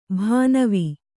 ♪ bhānavi